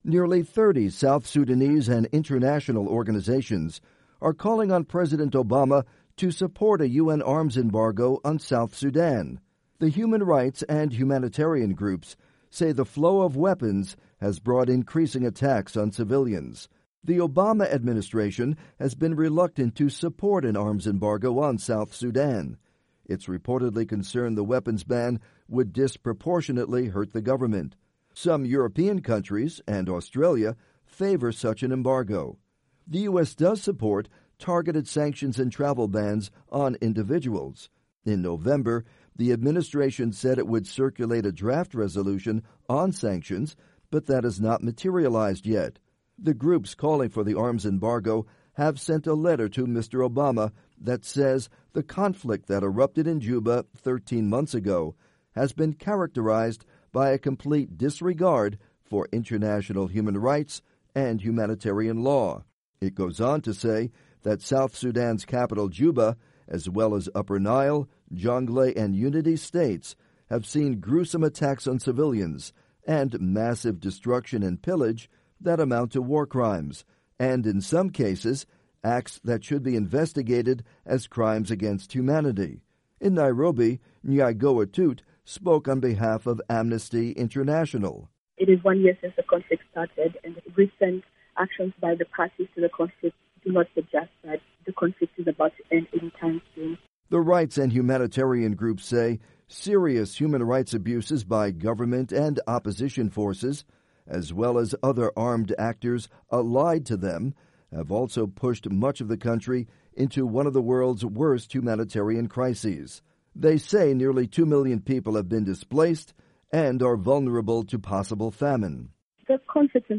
report on proposed arms embargo for South Sudan